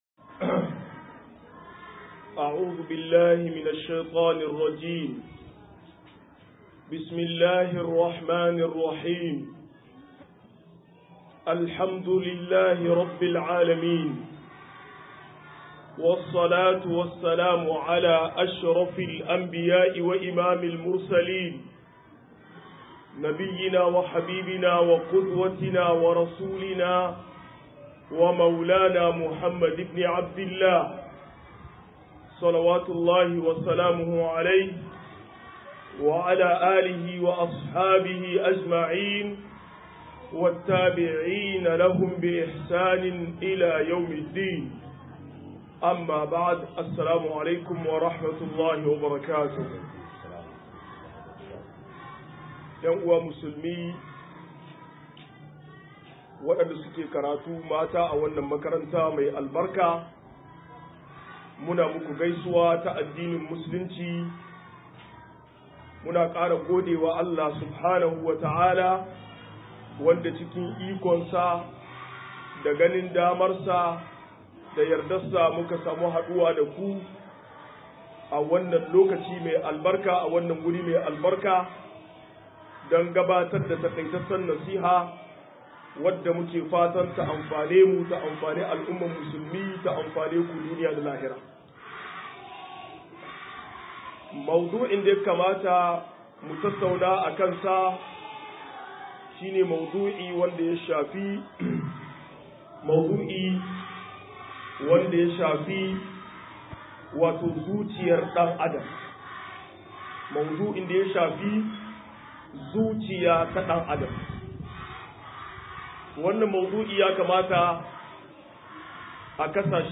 188-Rashin Lafiyar Zuciya - MUHADARA